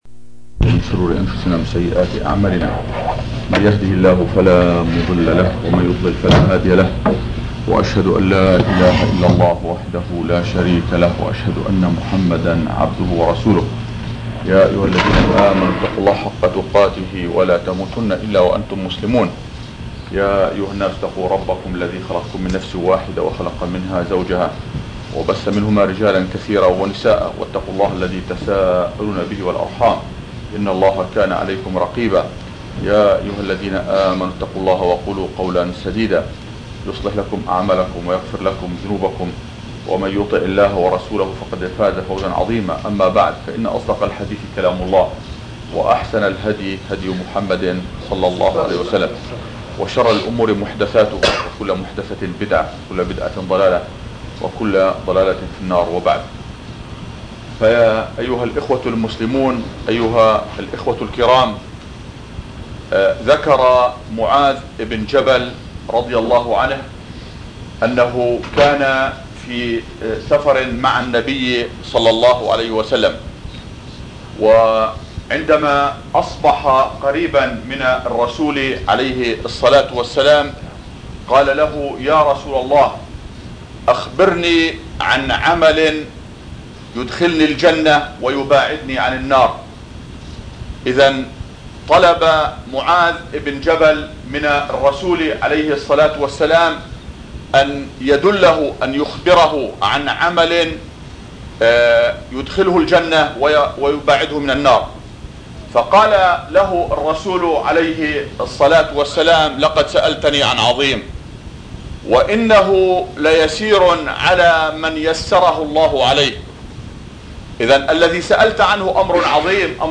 مواعظ